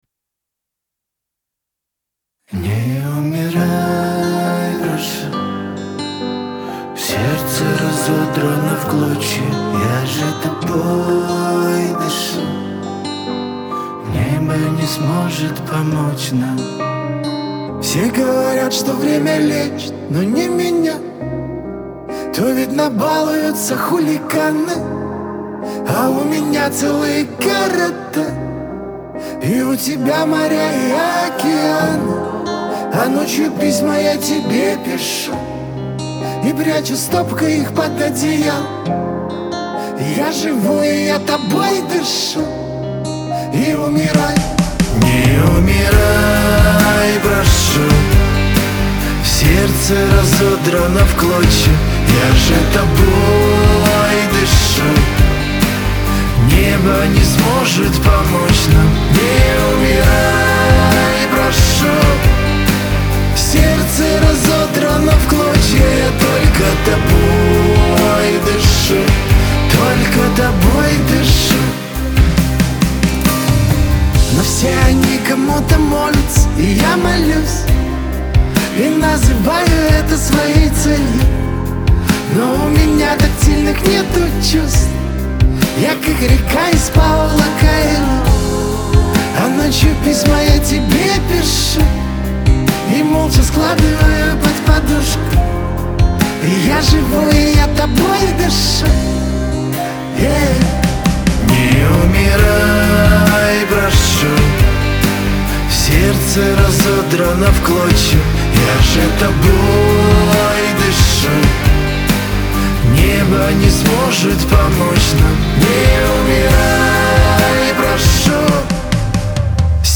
грусть
Шансон